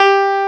CLAV2HRDG4.wav